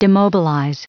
Prononciation du mot demobilize en anglais (fichier audio)
Prononciation du mot : demobilize